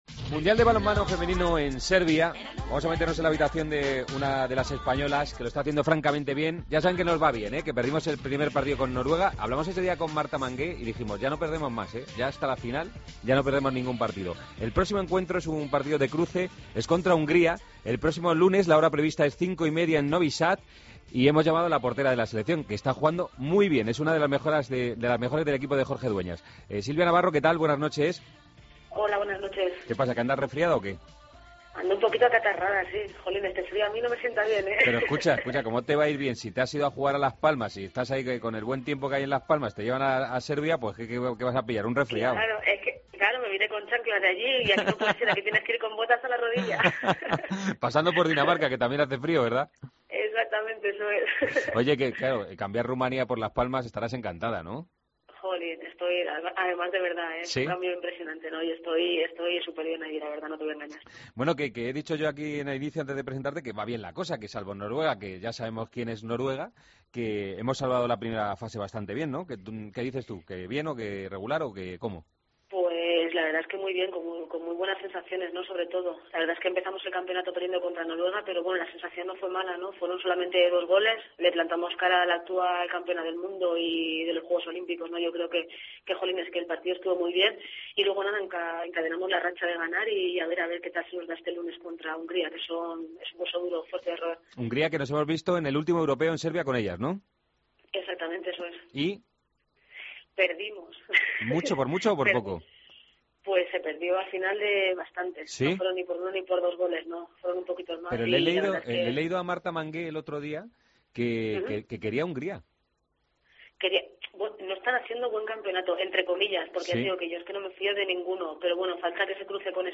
Redacción digital Madrid - Publicado el 15 dic 2013, 01:43 - Actualizado 02 feb 2023, 00:42 1 min lectura Descargar Facebook Twitter Whatsapp Telegram Enviar por email Copiar enlace Hablamos con la portero de la selección española de balonmano tras acabar la primera fase del Mundial de Serbia.